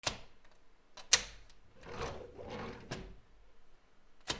扉
/ K｜フォーリー(開閉) / K05 ｜ドア(扉)
『チャ』